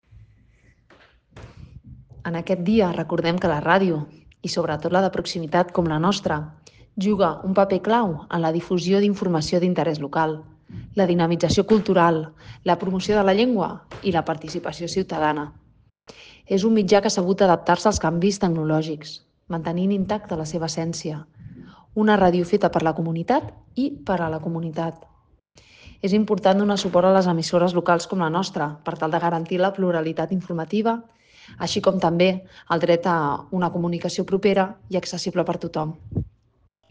Cristina Dalmau, regidora de Mitjans de Comunicació